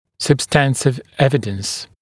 [səb’stæntɪv ‘evɪdəns][сэб’стэнтив ‘эвидэнс]доказательства по существу, чем-то подкрепленные доказательства